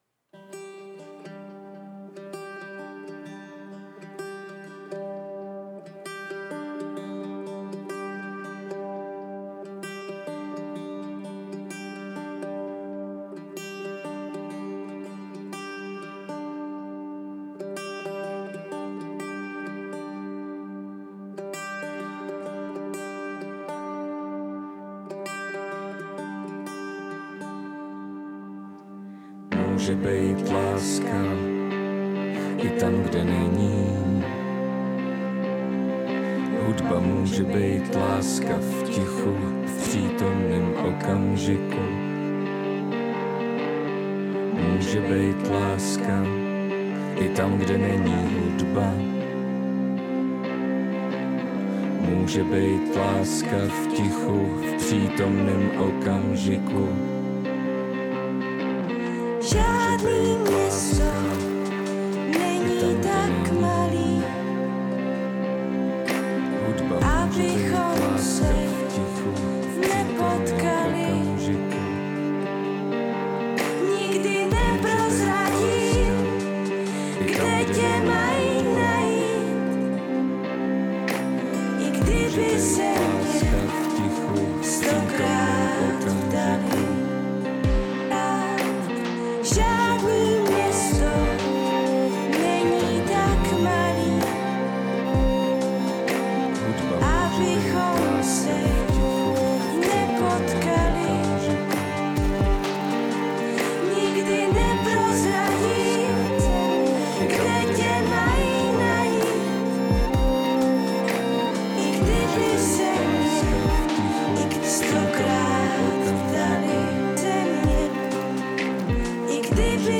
Temné písně noci o naději a světle.